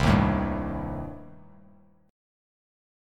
Cm6 Chord
Listen to Cm6 strummed